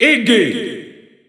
Announcer pronouncing Iggy in Italian.
Category:Bowser Jr. (SSBU) Category:Announcer calls (SSBU) You cannot overwrite this file.
Iggy_Italian_Announcer_SSBU.wav